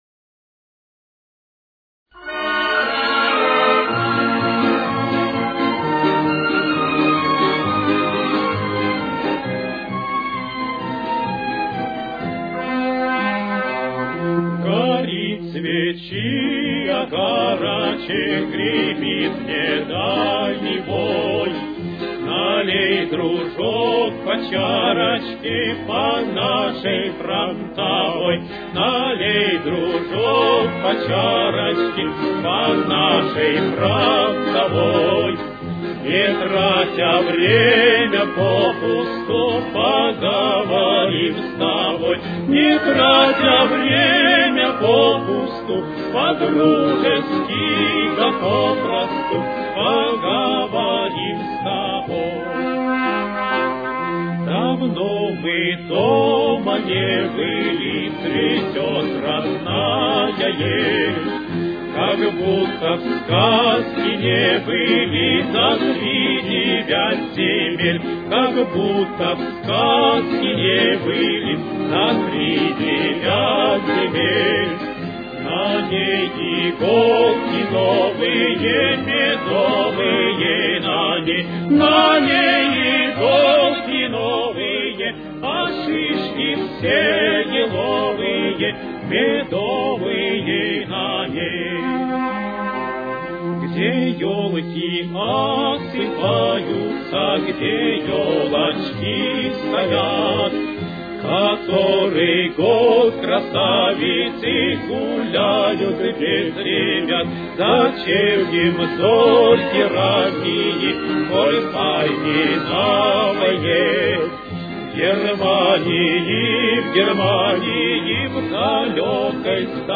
Фа минор. Темп: 67.